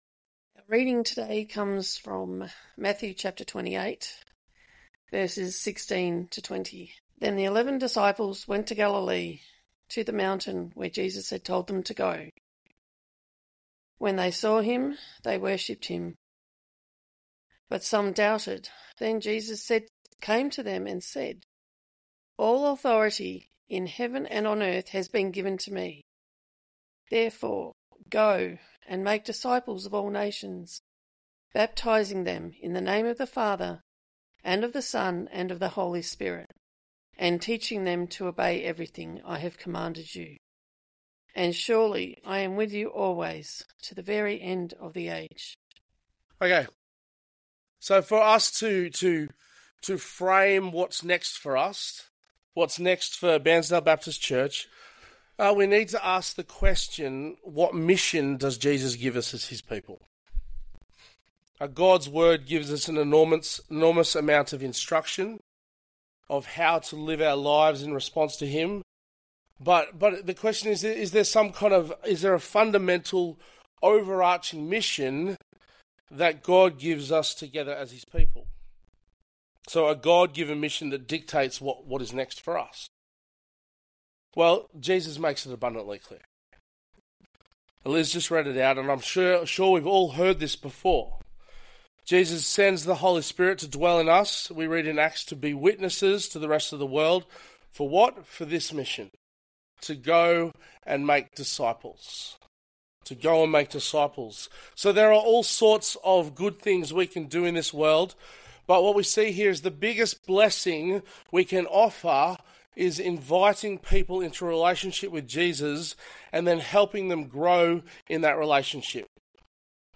Please note that this talk may seem disjointed, as there were times of discussion throughout that have not been recorded.